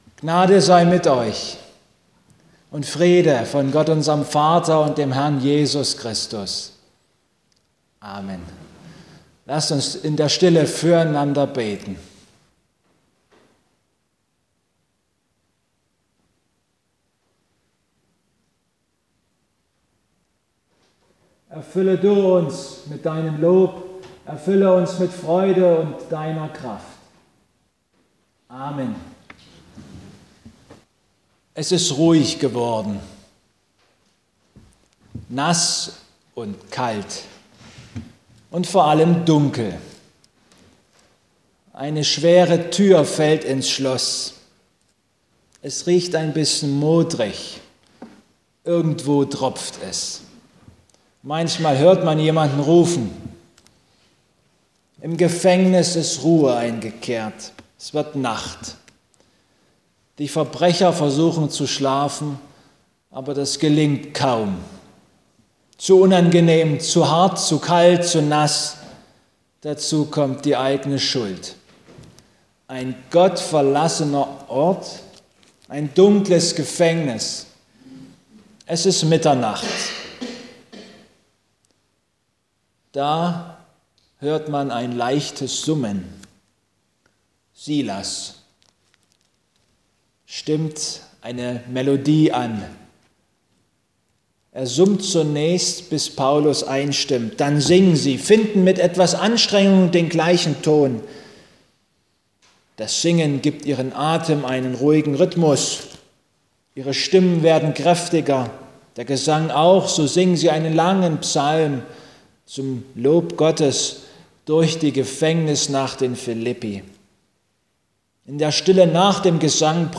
Apg 16.23-34 Gottesdienstart: Abendmahlsgottesdienst Am Musik-Sonntag